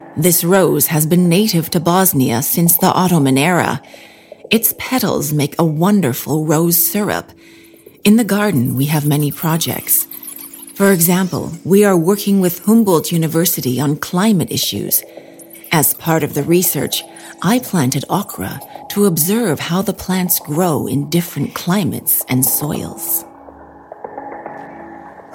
Locutor
Hablante nativo